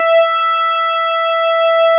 snes_synth_064.wav